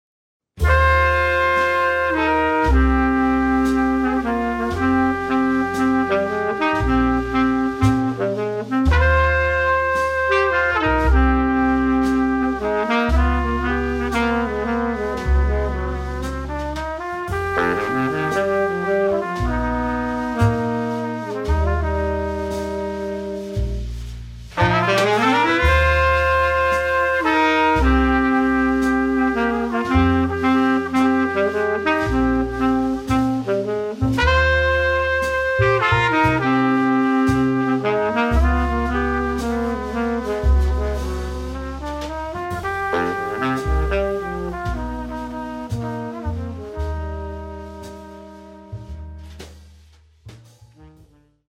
trumpet
sax
bass
drums